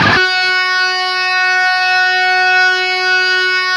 LEAD F#3 LP.wav